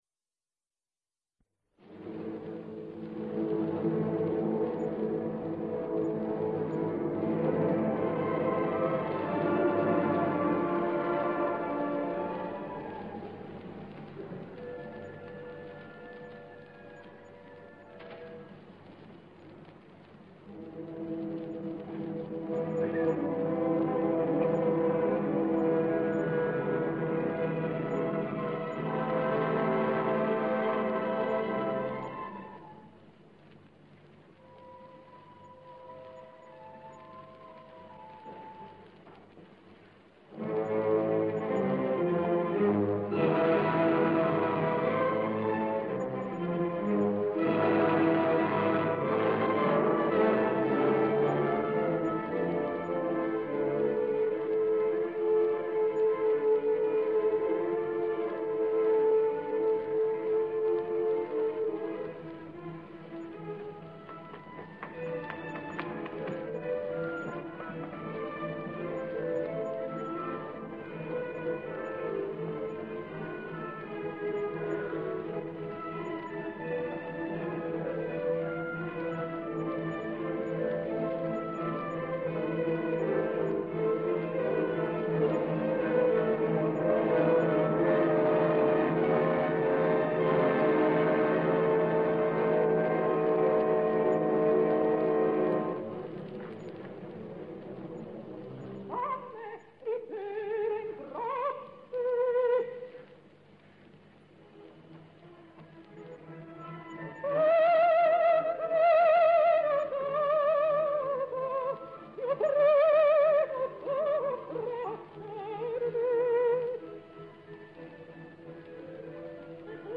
registrazione dal vivo.